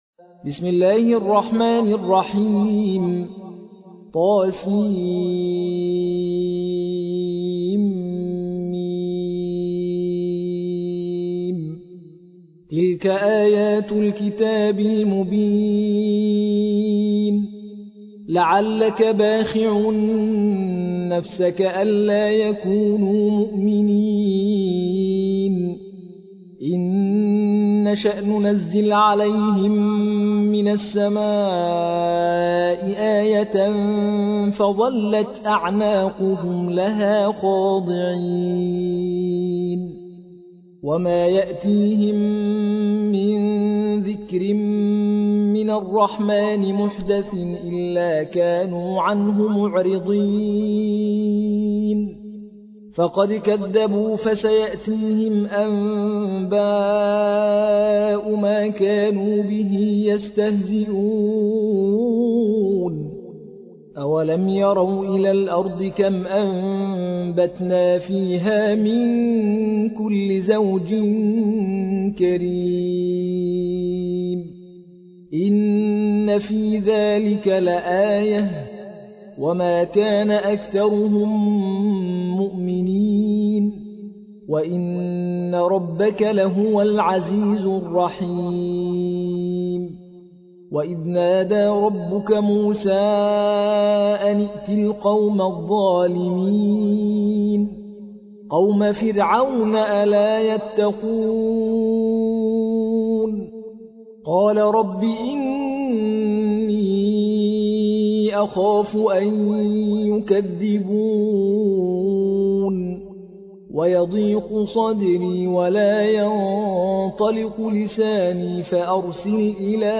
المصحف المرتل - حفص عن عاصم - Ash-Shuara ( The Poets )